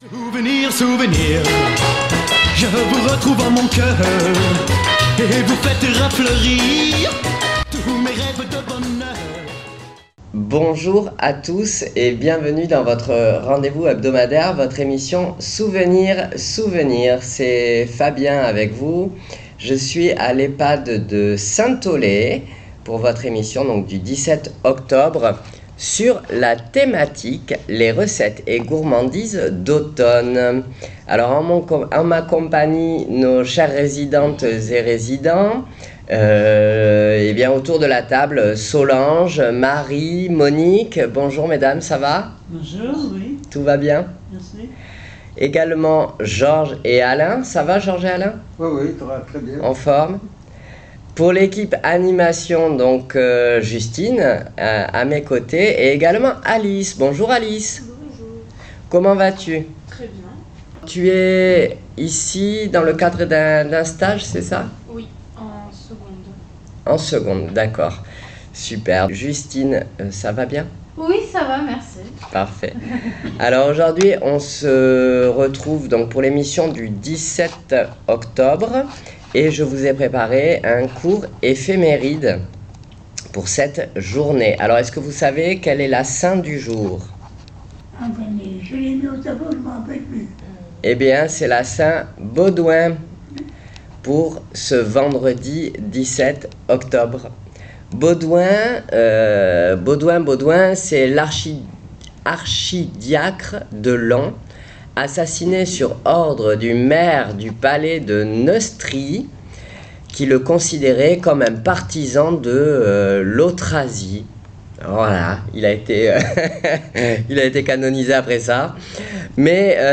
Souvenirs Souvenirs 17.10.25 à l'Ehpad de Saint Aulaye " Les recettes et gourmandises d'automne "